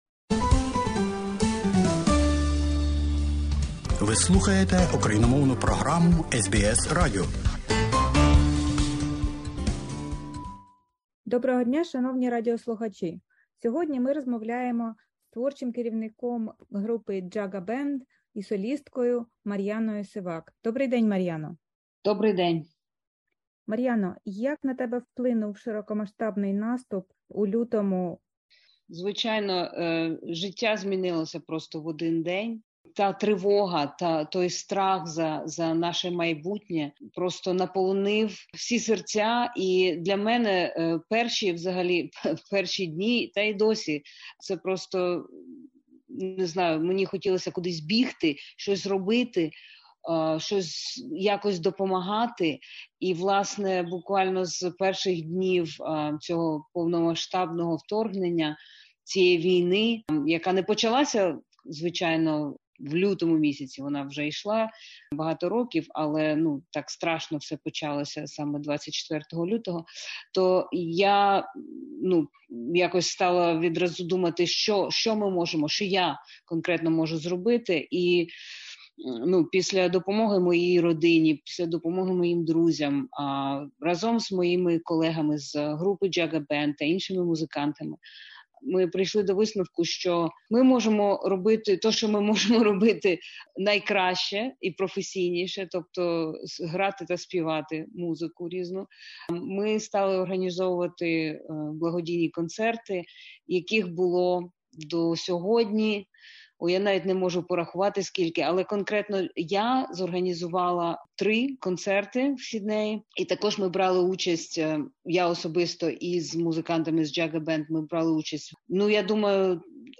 В інтервʼю